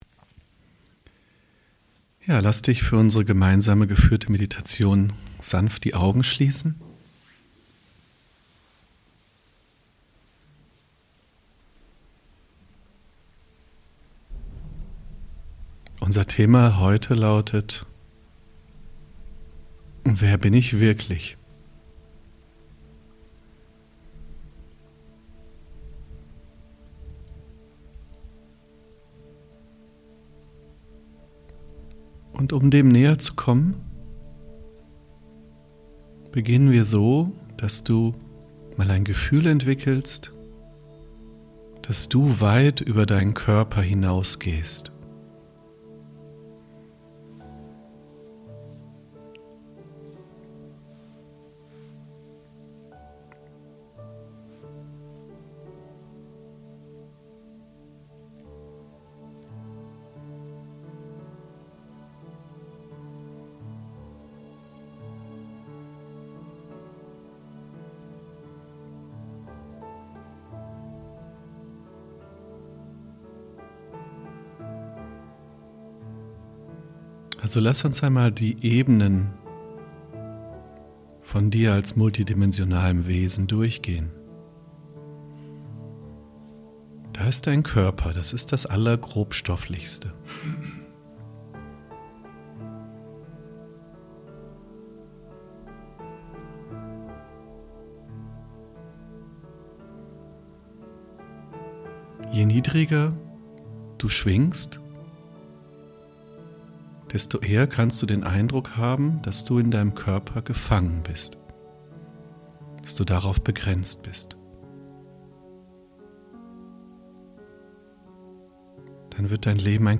DLwerbinichMeditation